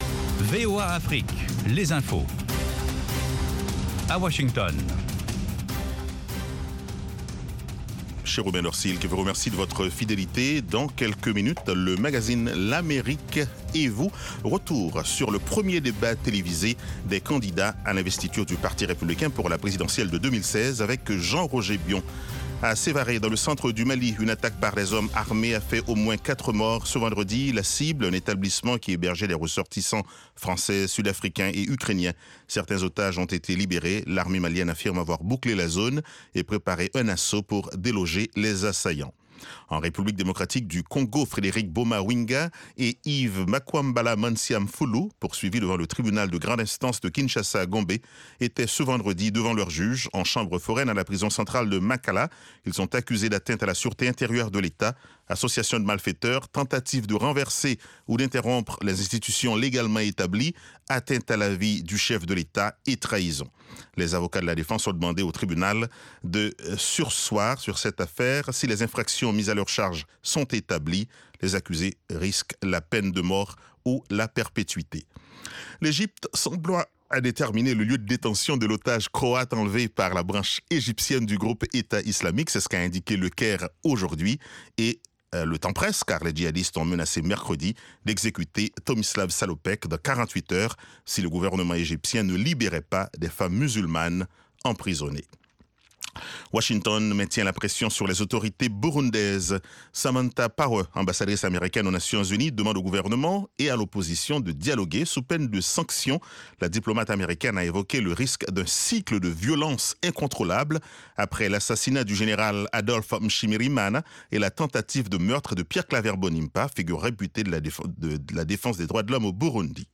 Newscast